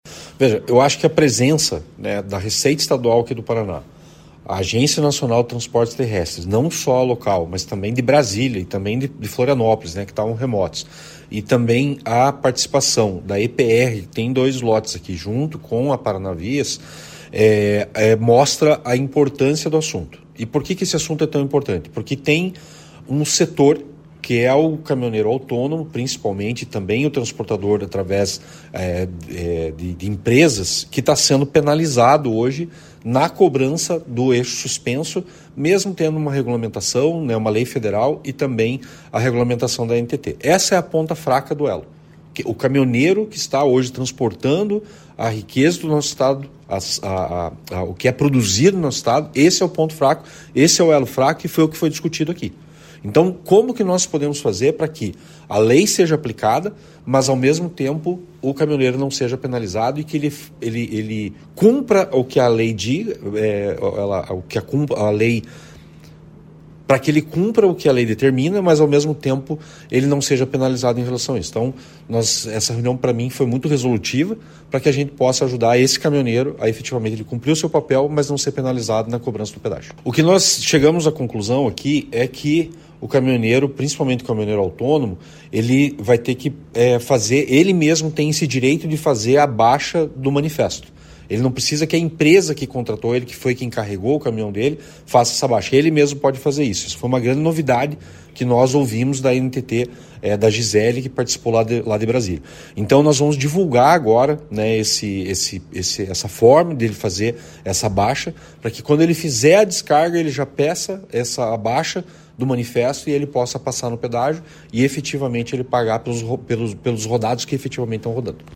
Ouça o que diz o deputado sobre o debate: